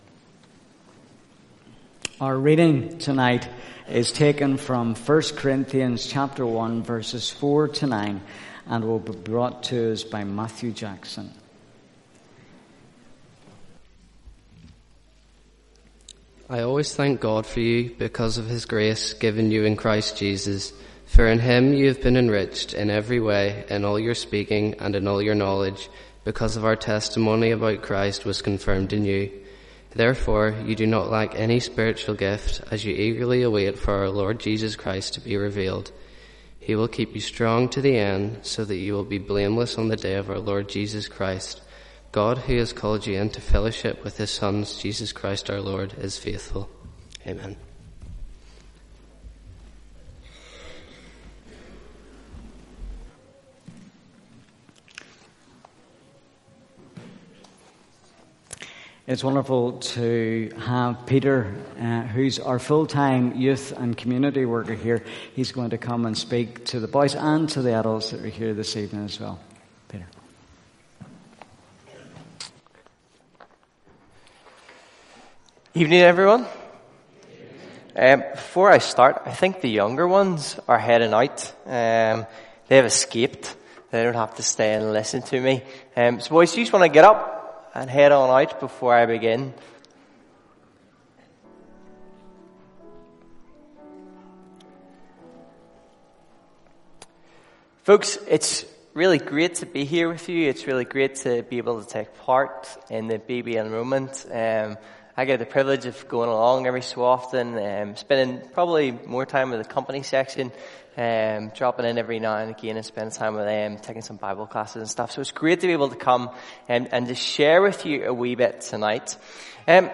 The BB Enrolment Service